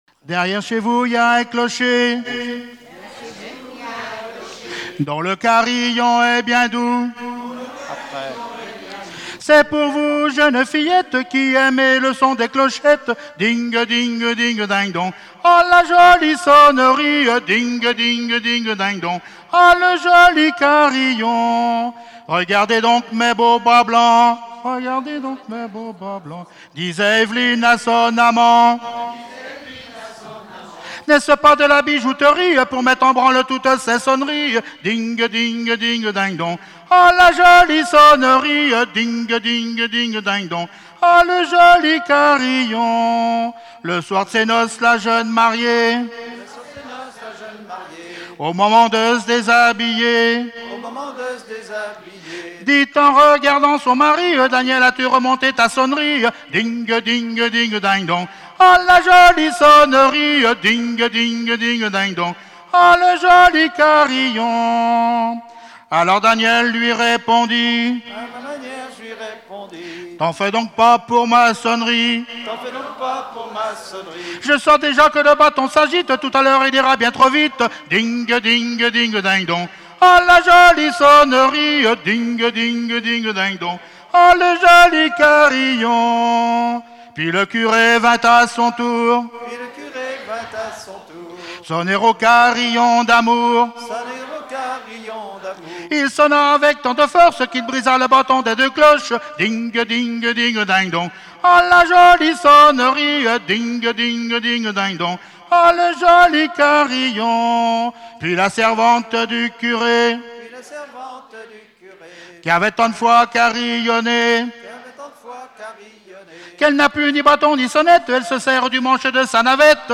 Genre laisse
Festival de la chanson traditionnelle - chanteurs des cantons de Vendée
Pièce musicale inédite